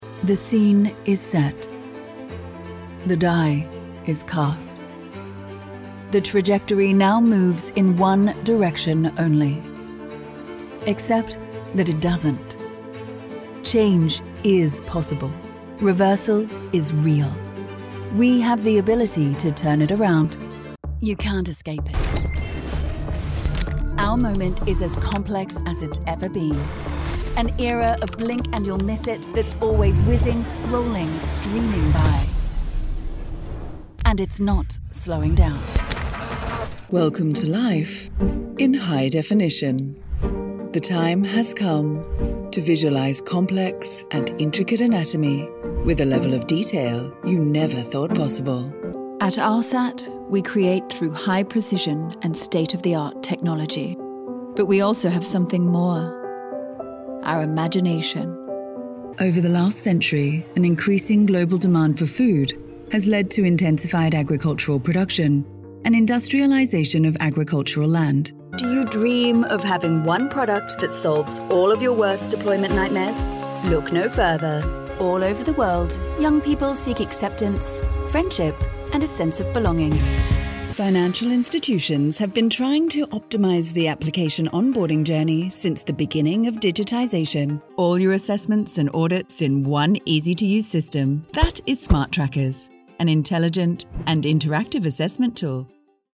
Female
English (British), English (Australian), English (Neutral - Mid Trans Atlantic)
Warm, clear, versatile and seriously experienced.
My voice carries a conversational, soothing maturity, with a natural warmth that adds depth and resonance to your script.
Corporate Demo - Mixed Accents
All our voice actors have professional broadcast quality recording studios.